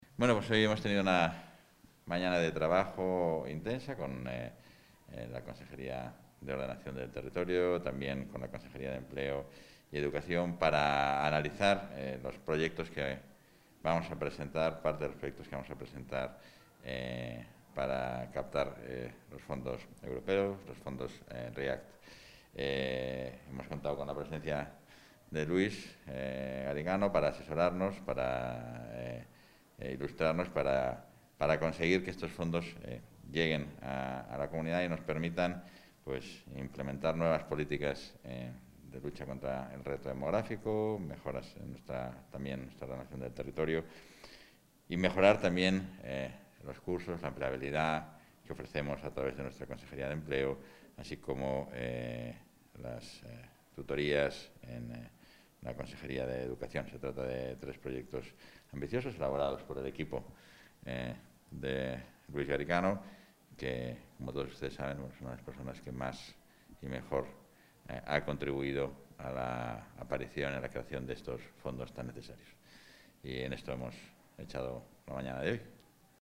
Declaraciones del vicepresidente de la Junta, Francisco Igea, y el europarlamentario Luis Garicano al término del encuentro para abordar proyectos vinculados a fondos europeos | Comunicación | Junta de Castilla y León
Valoración del vicepresidente.